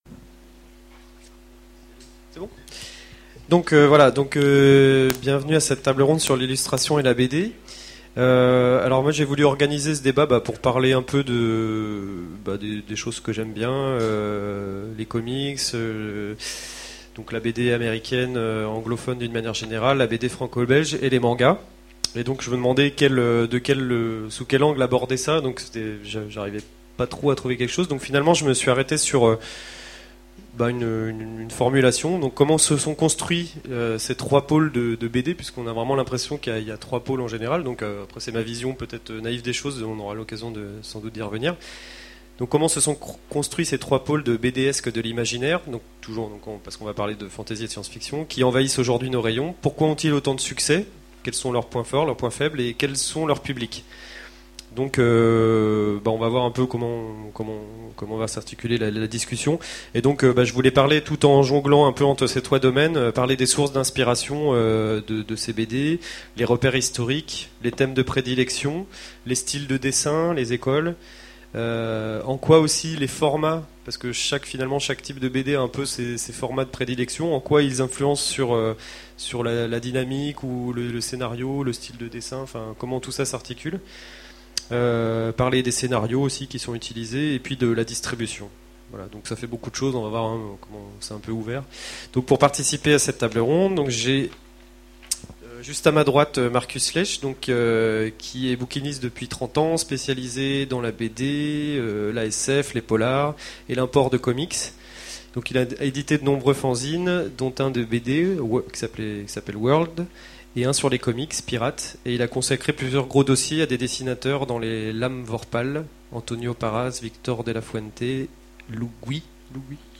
Voici l'enregistrement de la conférence L'illustration et la BD à la convention 2010